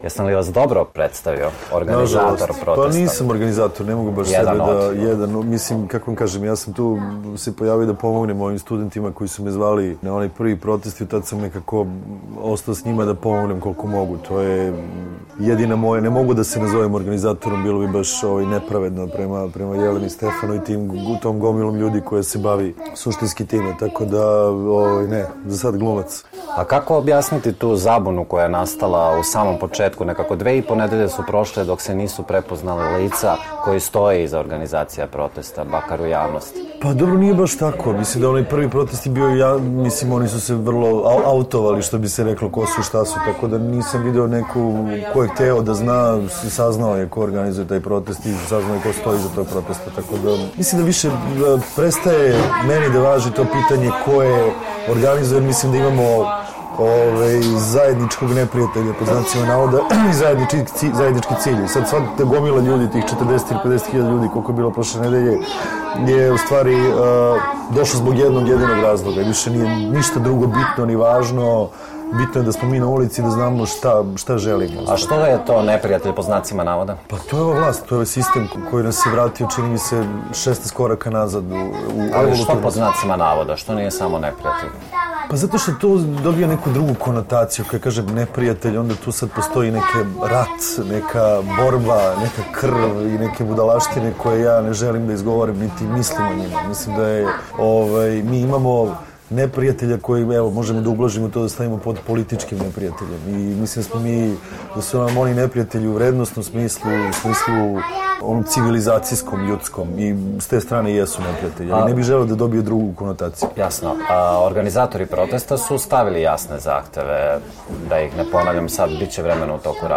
Intervju nedelje: Branislav Trifunović